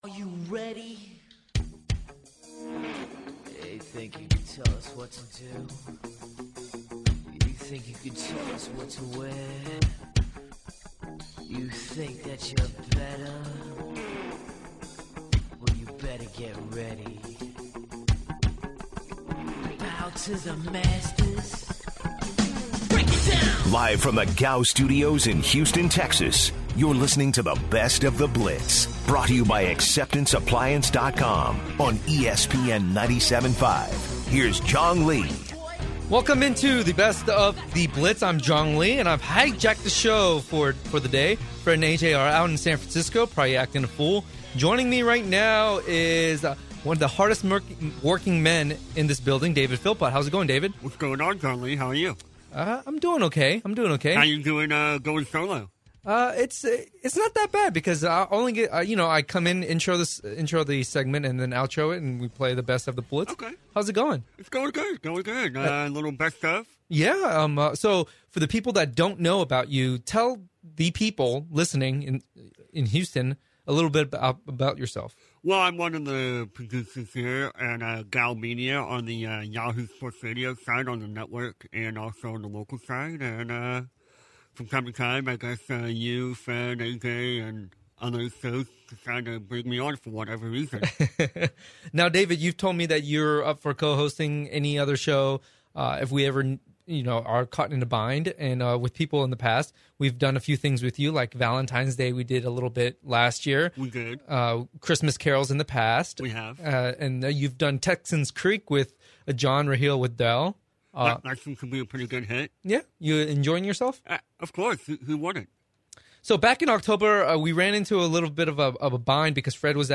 Next up are highlights from the interview with Tom Herman of the University of Houston when they talked about what a great season UH football had this past year.